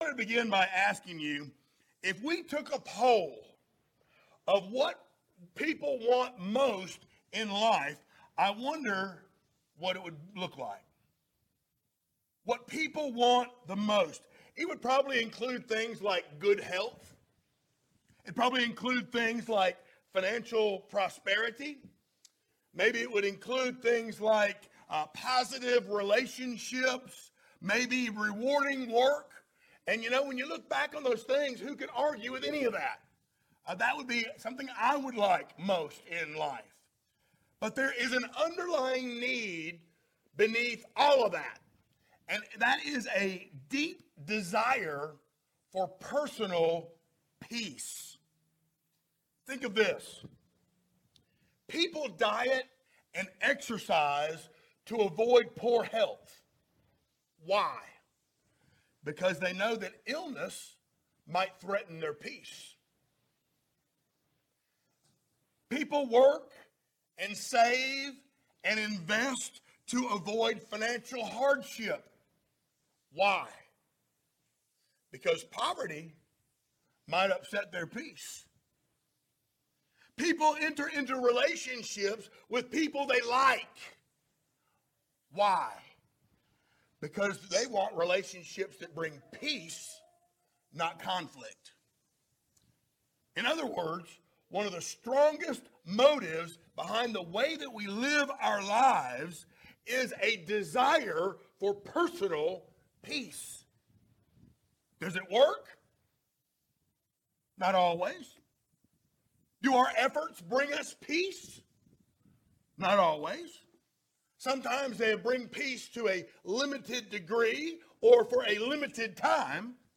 Series: sermons
Isaiah 26:3-4 Service Type: Sunday Morning Download Files Notes Topics